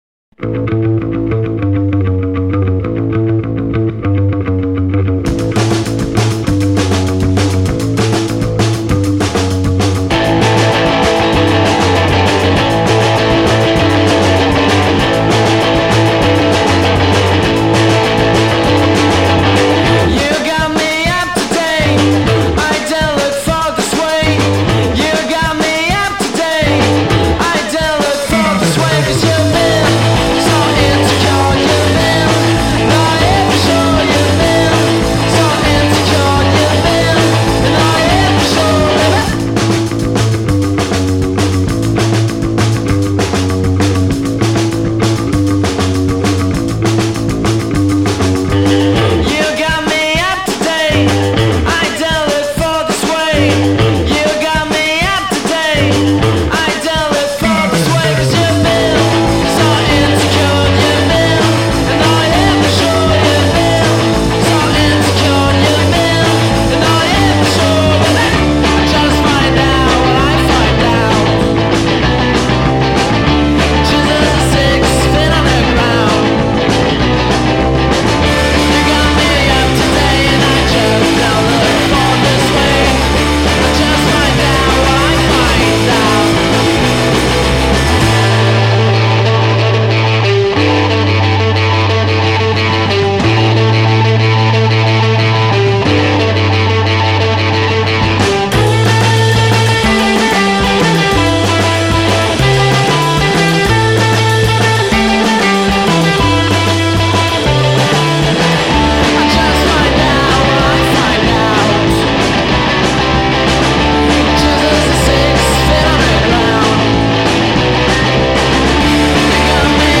Parisian garage-punk and surf rock band
beautifully primal single